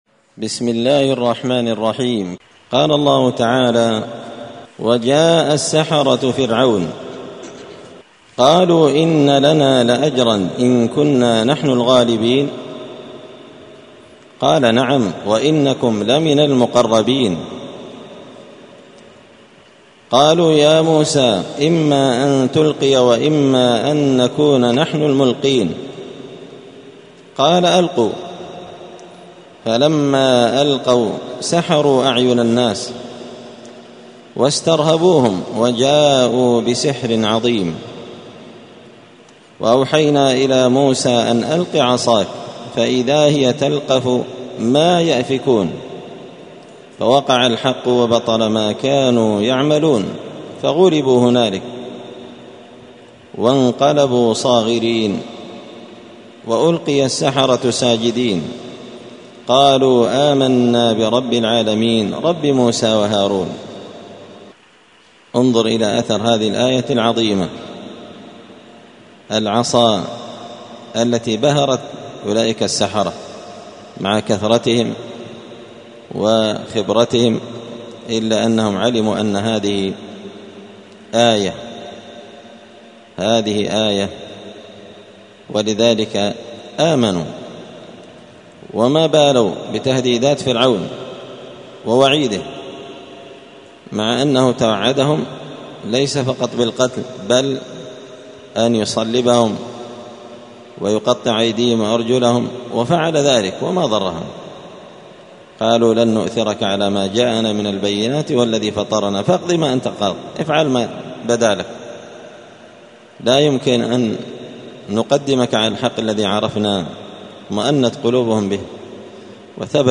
📌الدروس اليومية